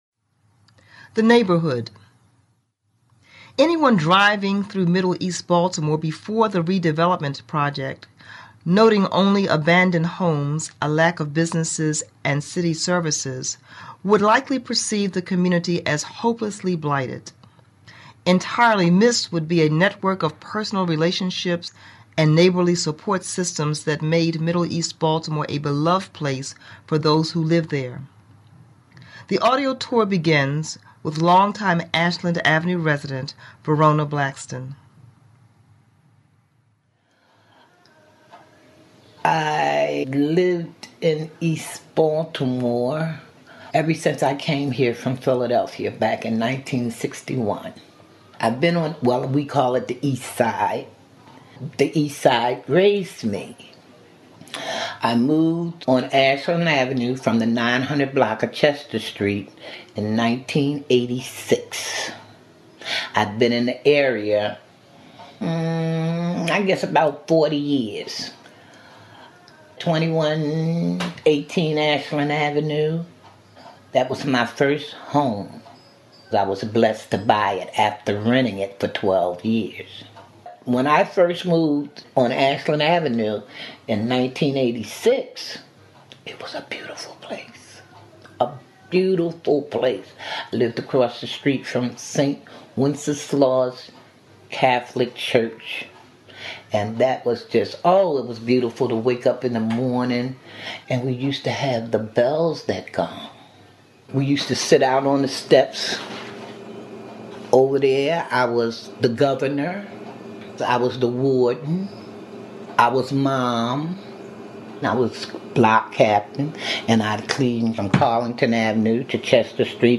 For this project, I recorded hours of personal stories, transcribed interviews, and made photographs of the interviewees and their community.